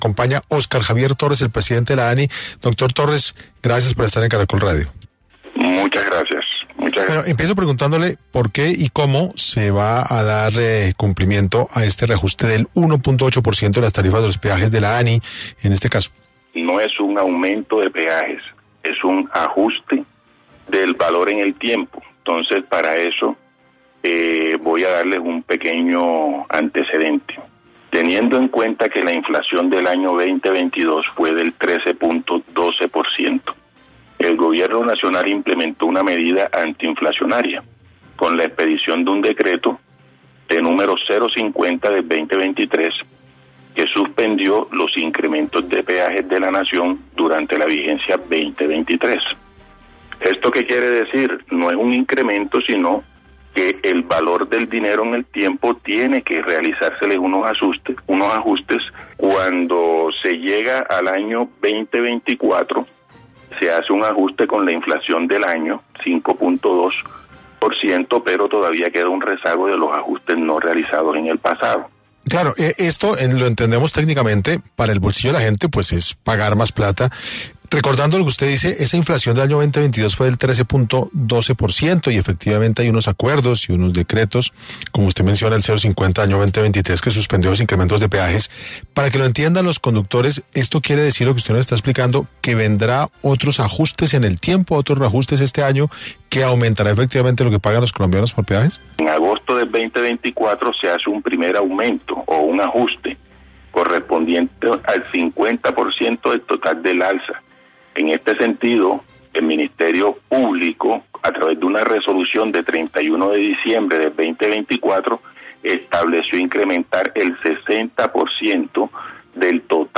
Hoy en entrevista para 6AM, Óscar Javier Torres, Presidente de la Agencia Nacional de Infraestructura (ANI), aclaró la situación y explicó a los colombianos el porqué de la medida.